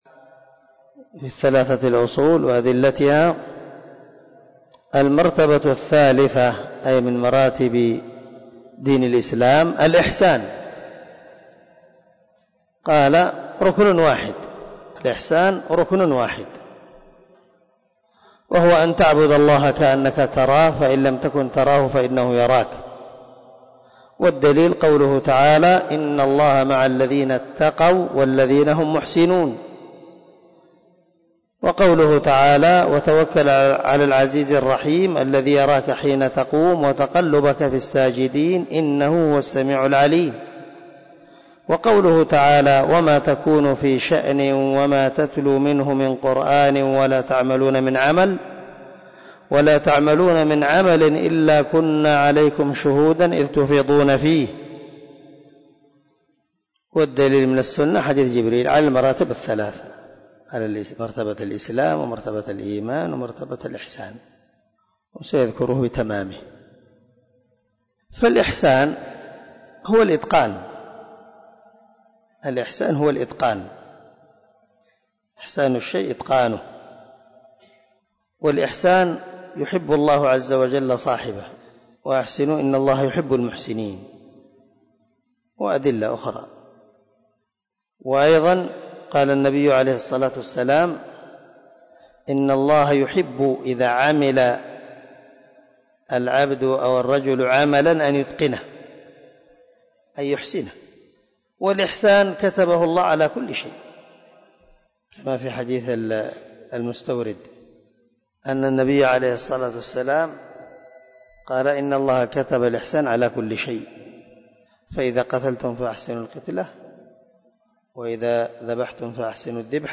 🔊 الدرس 29 من شرح الأصول الثلاثة
الدرس-29-المرتبة-الثالثة-الإحسان.mp3